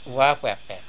The sample used for this phonetic study was collected in the field during my ethnographic research undertaken in the Middle Caquetá region of the Colombian Amazon between 1994 and 1996.